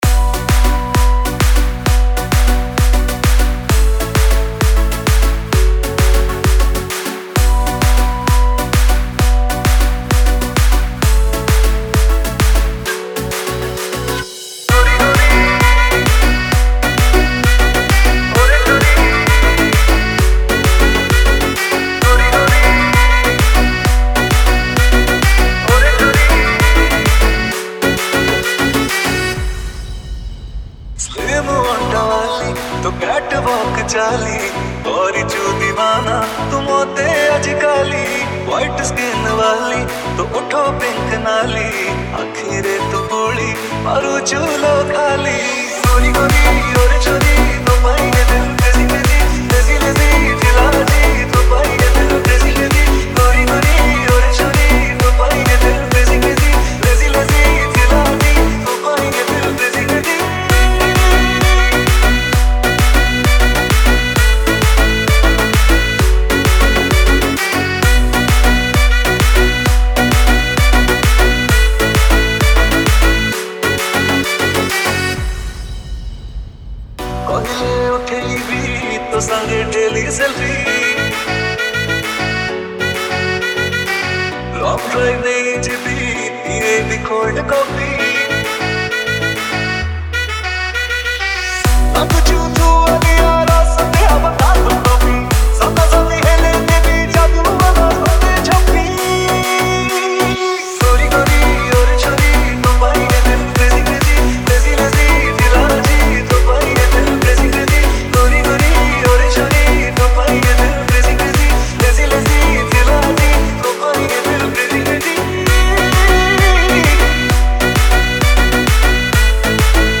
Romantic Love Dj Remix Songs Download